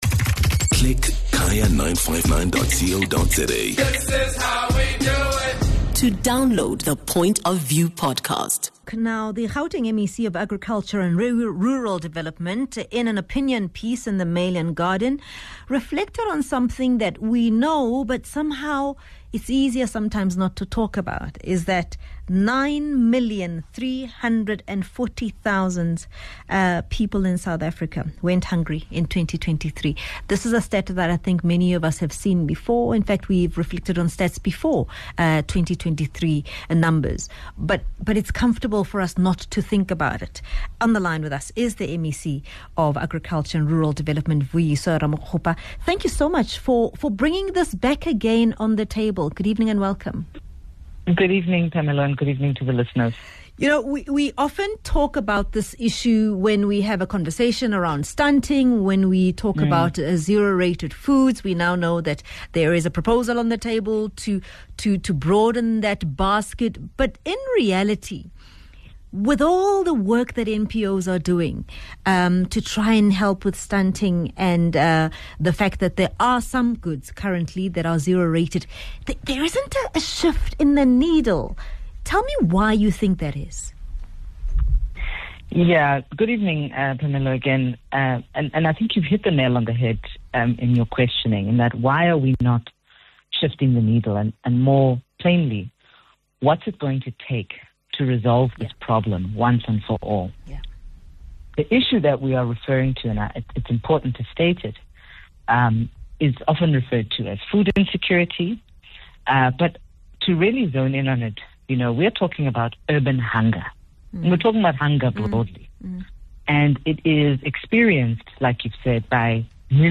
is in conversation with Ramokgopa about this complex issue that is faced by many South Africans.